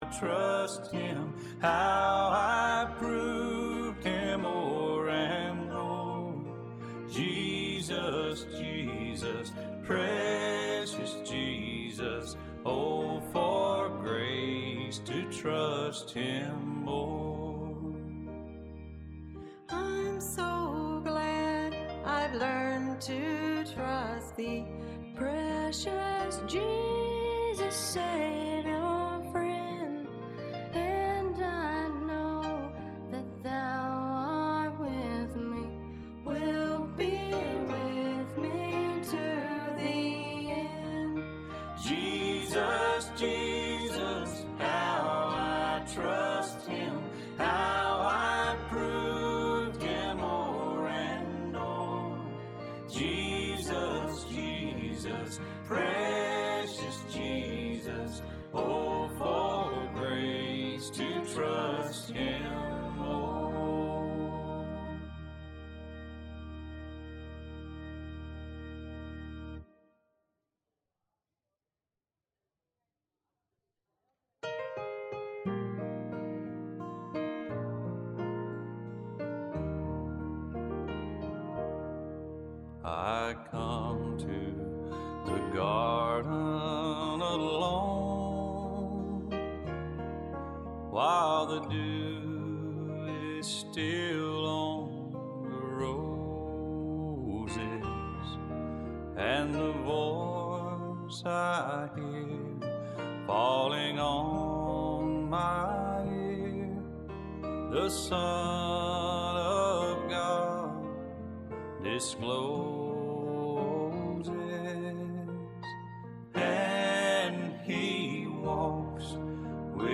Arlington Baptist Church Sermons